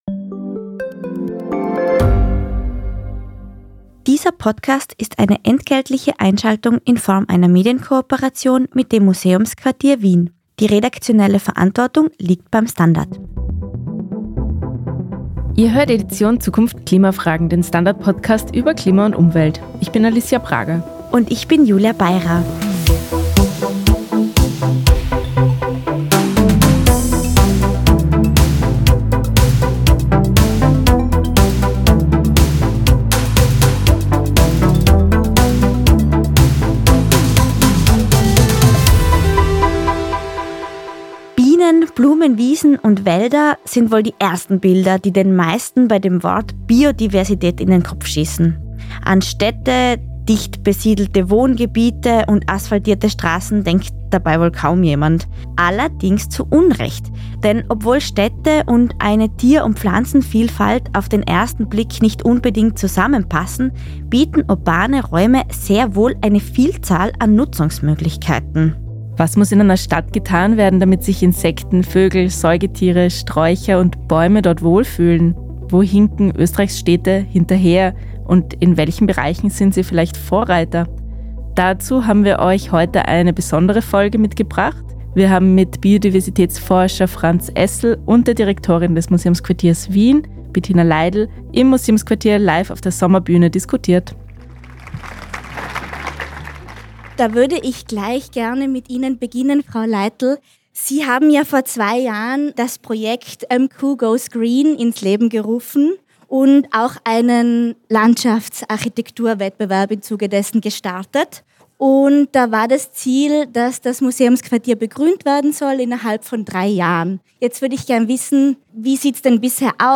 Edition Zukunft ist der STANDARD-Podcast über das Leben und die Welt von morgen. Die Redaktion spricht mit Experten über Entwicklungen, die unseren Alltag verändern - von künstlicher Intelligenz und Robotern bis hin zu Migration und Klimawandel.